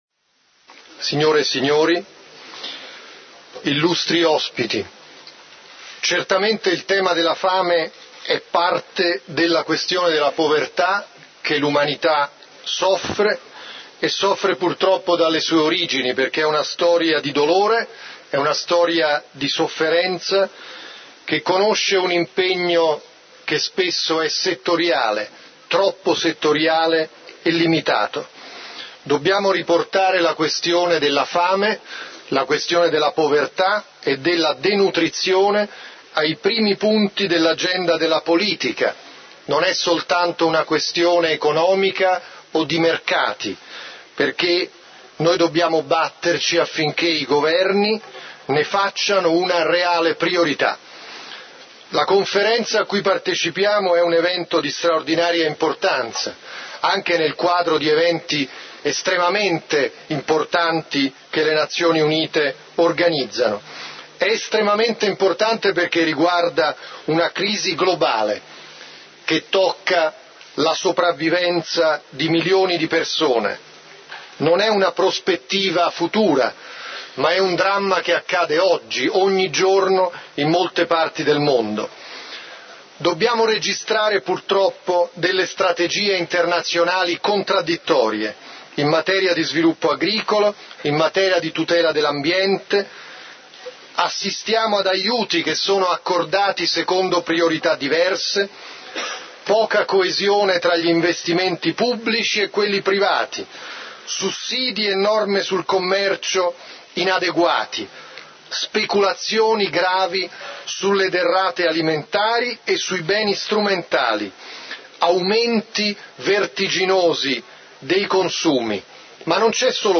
FAO - Conferenza ad Alto livello sulla sicurezza alimentare mondiale - intervento del Ministro degli esteri italiano